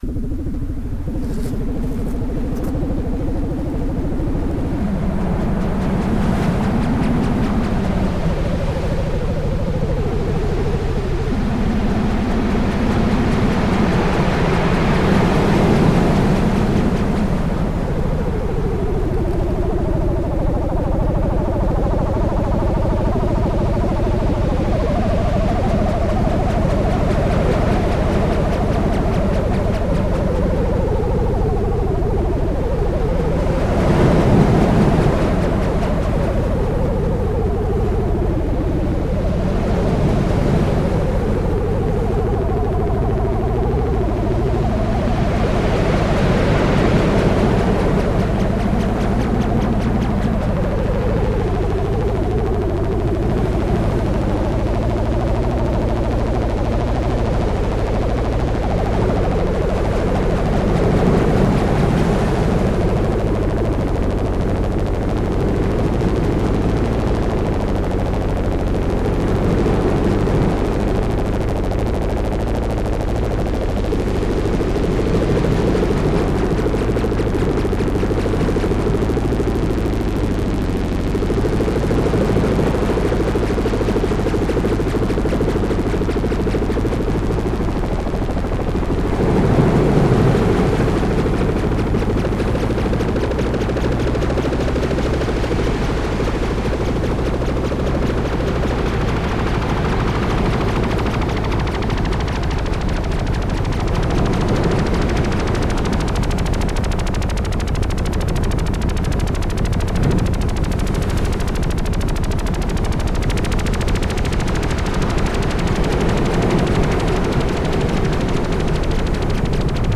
Korg MS-20